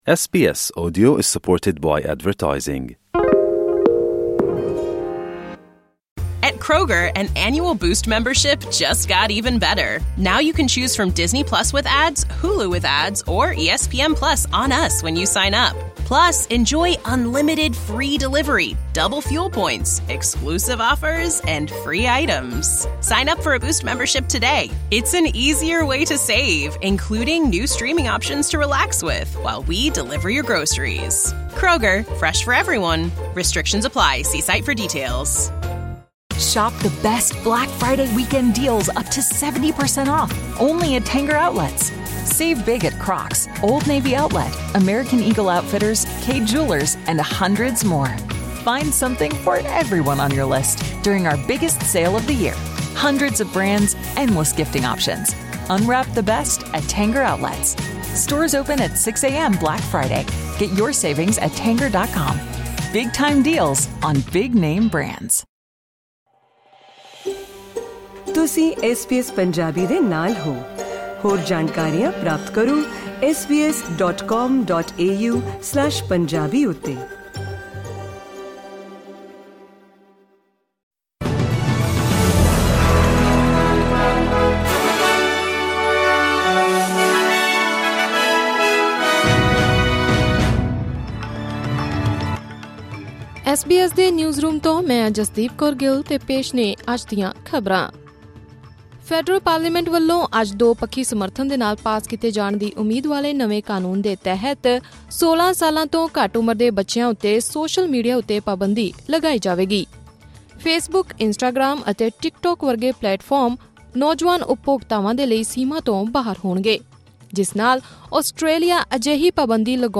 ਅੱਜ ਦੀਆਂ ਹੋਰ ਮੁੱਖ ਰਾਸ਼ਟਰੀ ਅਤੇ ਅੰਤਰਰਾਸ਼ਟਰੀ ਖਬਰਾਂ ਜਾਨਣ ਲਈ ਸੁਣੋ ਐਸ ਬੀ ਐਸ ਪੰਜਾਬੀ ਦੀ ਇਹ ਆਡੀਓ ਪੇਸ਼ਕਾਰੀ...